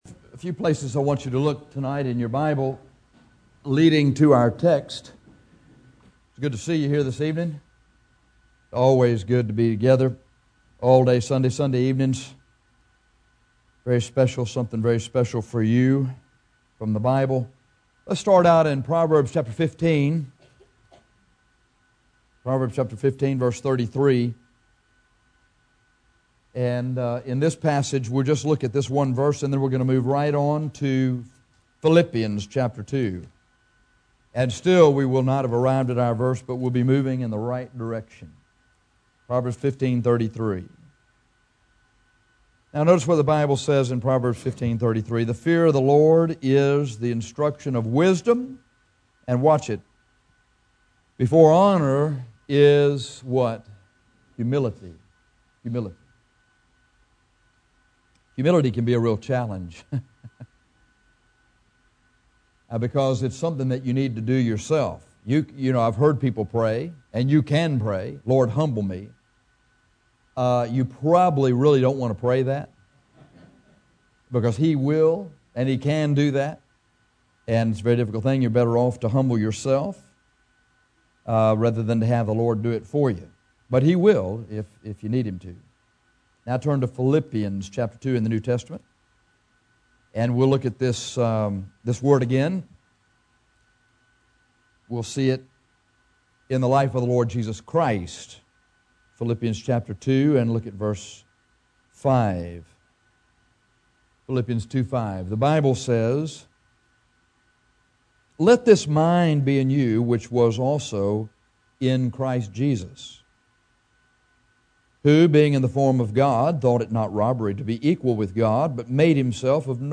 In 1 Pet 5:5-9 we find four great benefits of humility. This sermom contains many helpful illustrations.